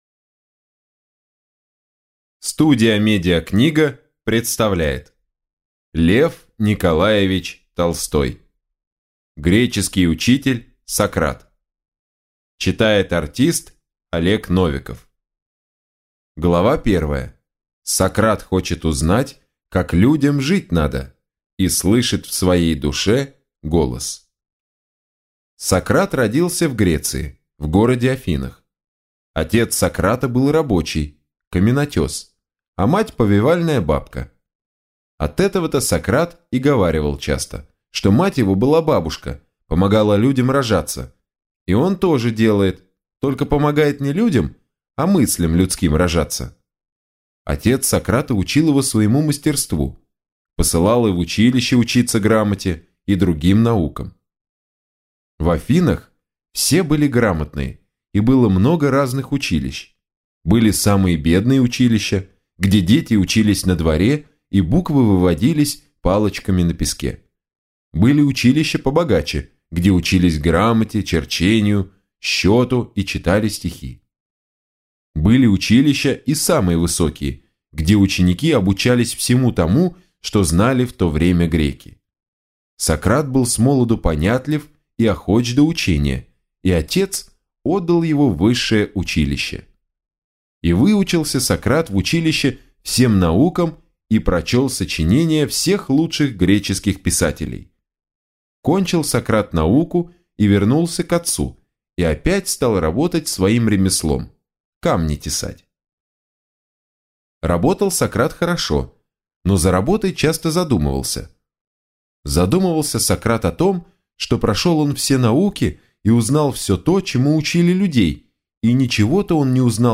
Аудиокнига Греческий учитель Сократ | Библиотека аудиокниг